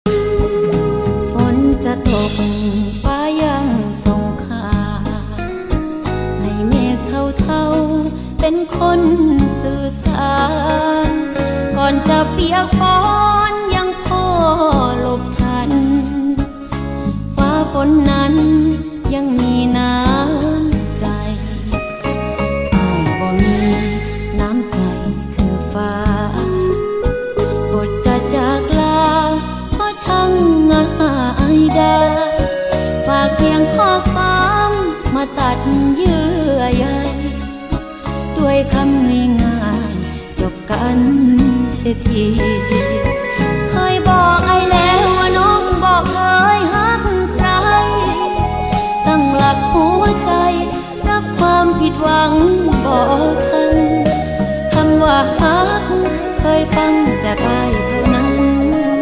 ฝนตก sound effects free download